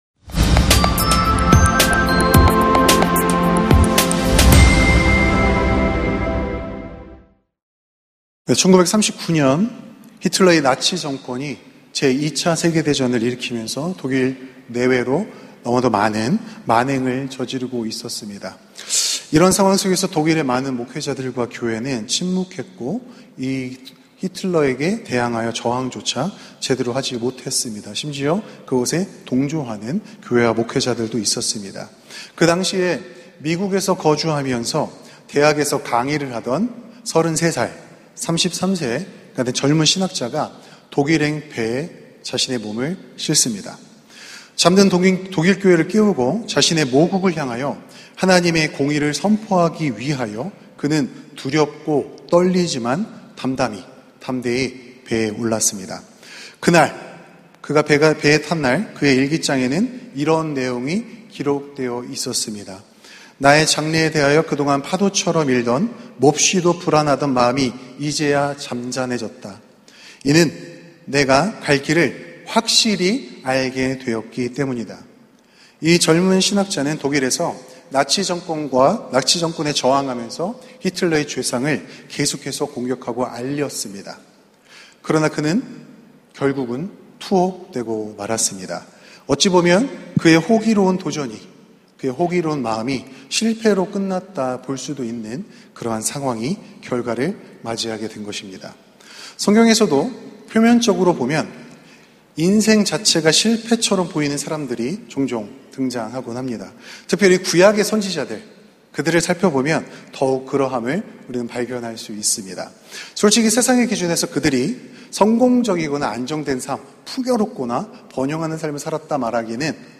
설교